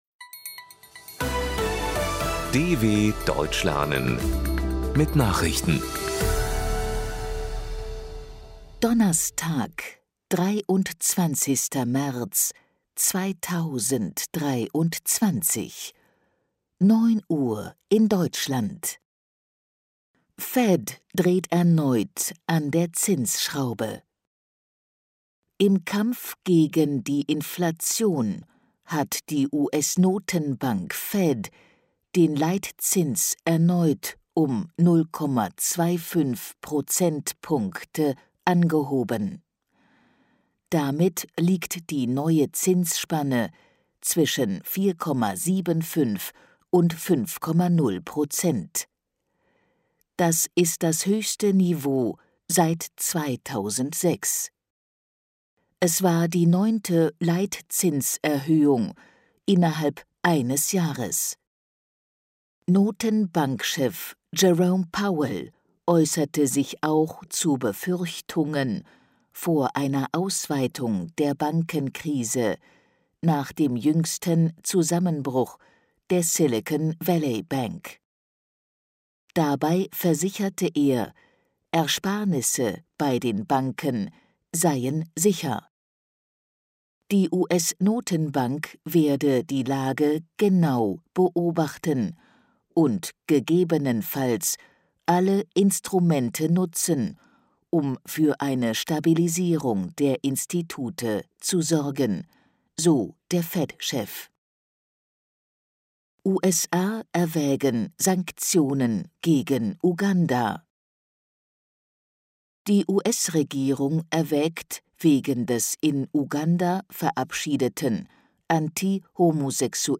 Die Langsam gesprochenen Nachrichten der DW bieten von Montag bis Samstag aktuelle Tagesnachrichten aus aller Welt. Das langsam und verständlich gesprochene Audio trainiert das Hörverstehen.